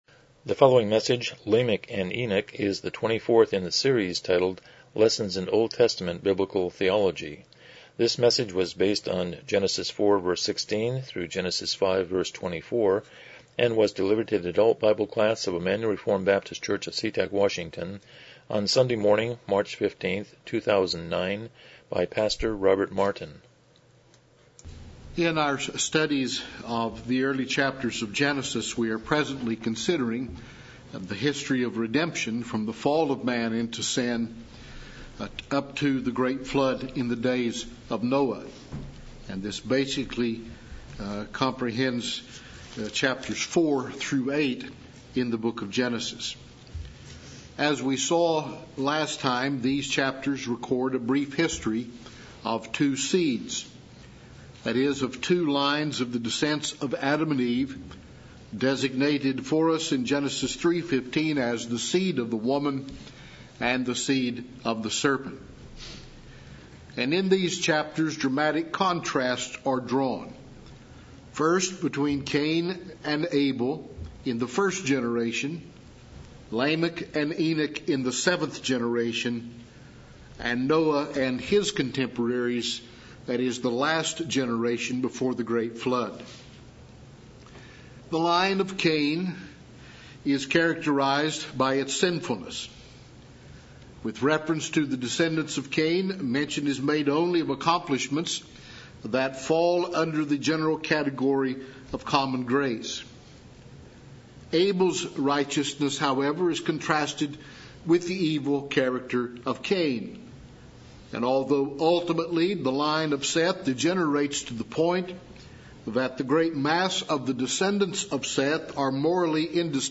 Genesis 4:16-5:24 Service Type: Sunday School « 73 Romans 6:4 74 Romans 6:4